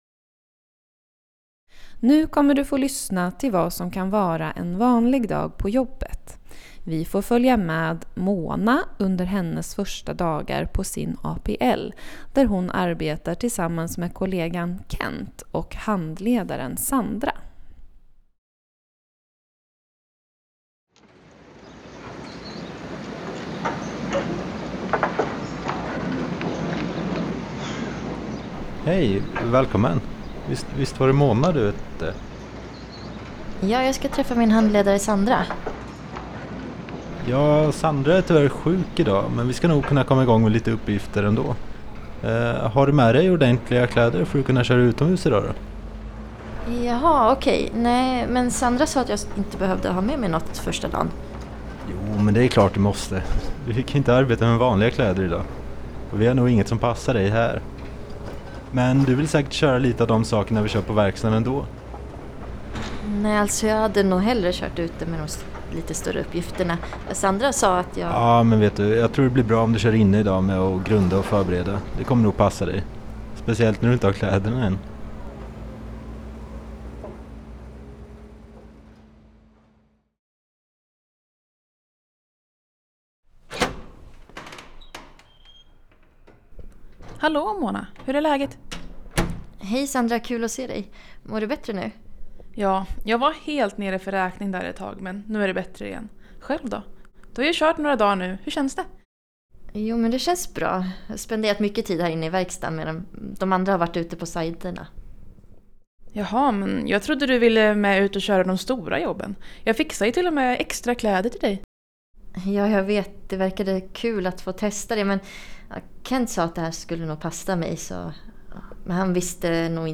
Övningen fokuserar på fem olika scenarion i radioteaterform som bygger på erfarenheter från svenska arbetsplatser.